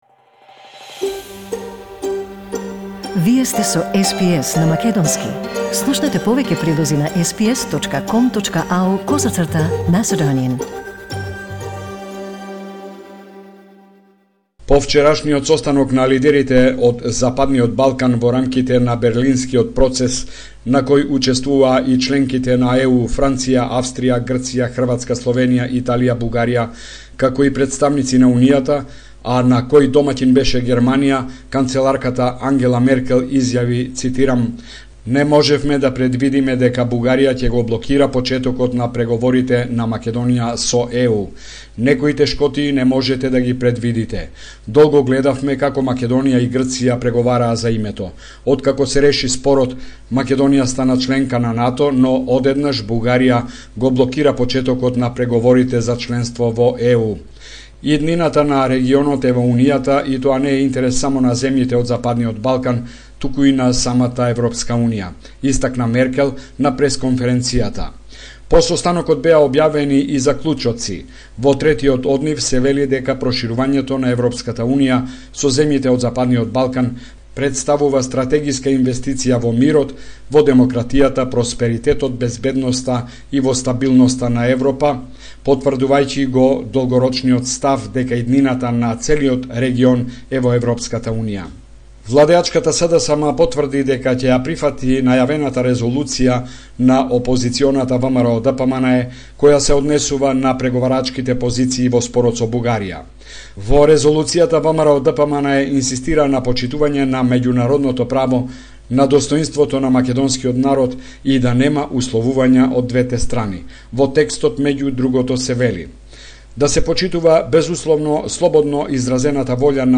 Homeland Report in Macedonian 6 July 2021